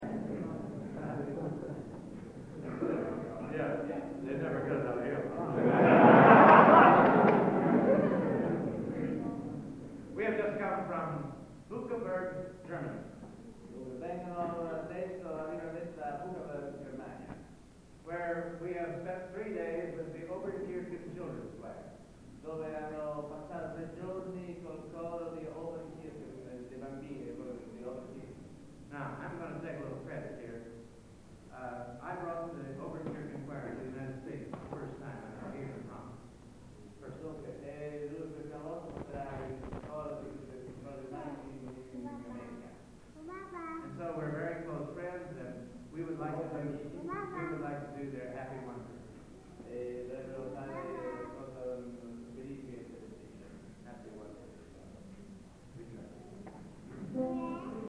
Location: Florence, Italy
Genre: | Type: Director intros, emceeing